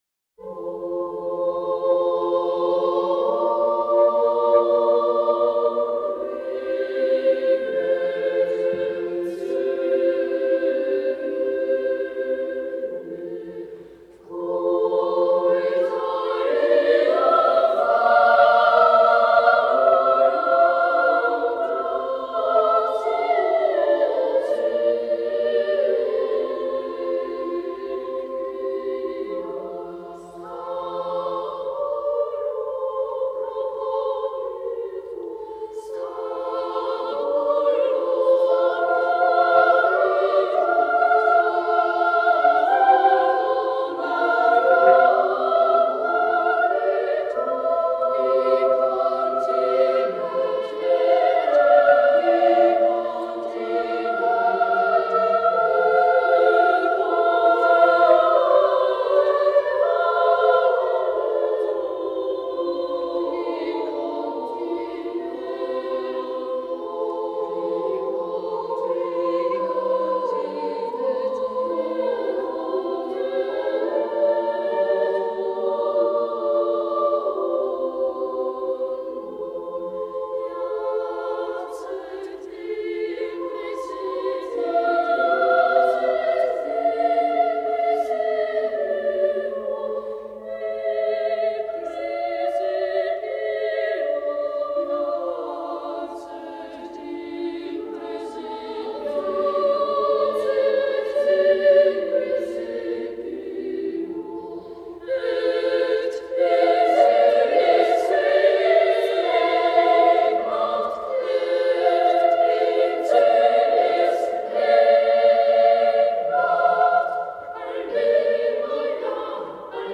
The following is a simple Motete:  O Regem coeli, Natus est nobis
This entry was posted in Musica Sacra and tagged , , , , .